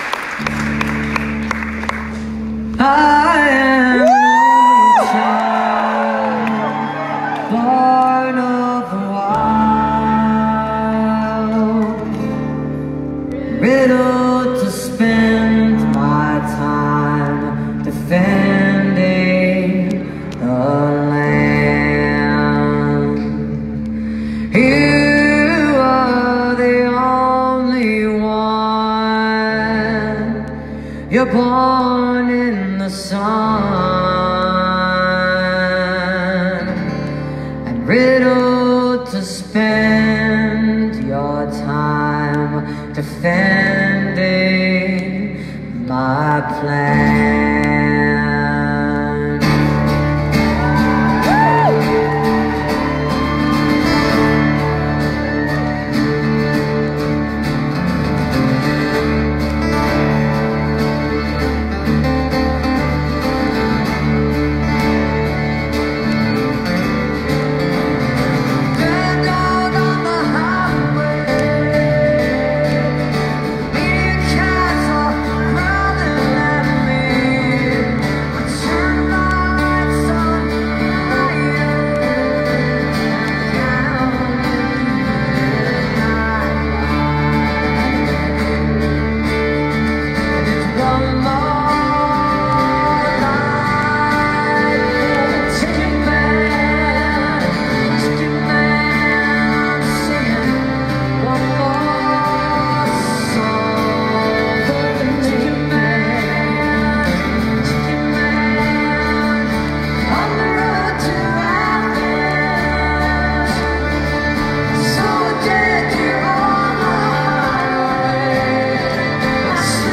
(captured from facebook live streams)